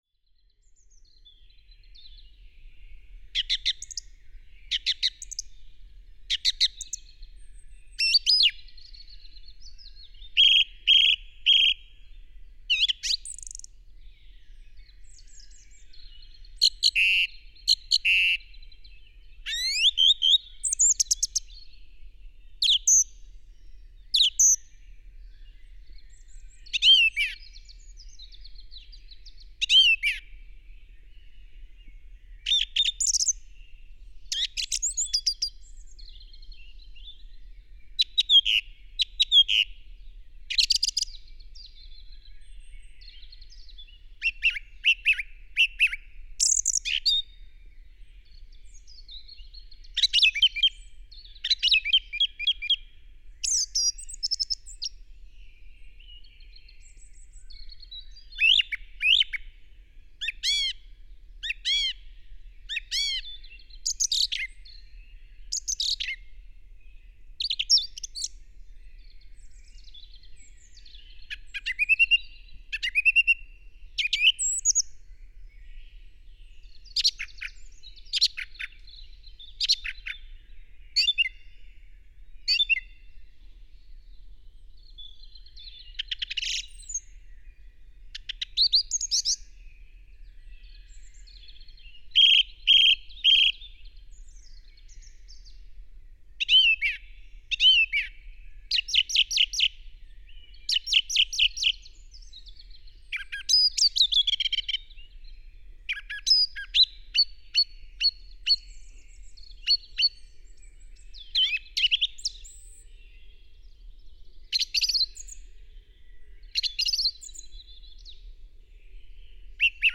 has been always the same: calls of small passerines.
low-noise forests.